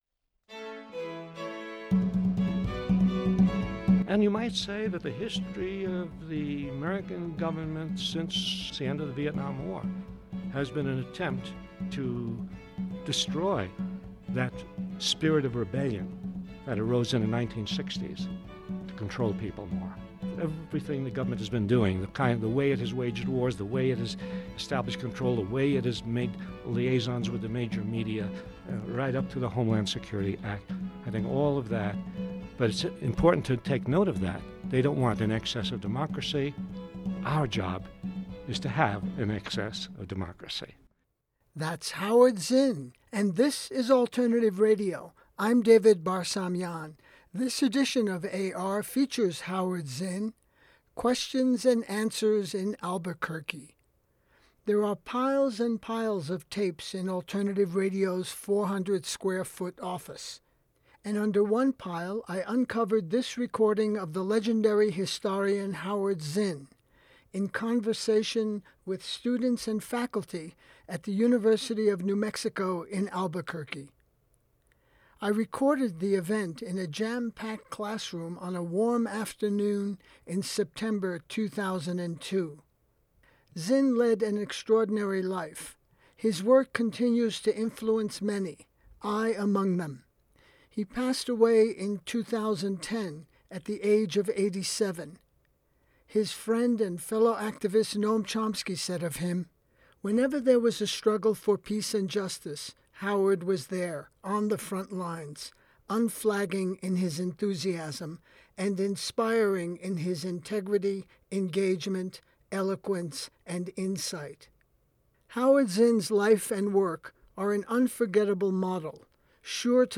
Howard Zinn – Q&A in Albuquerque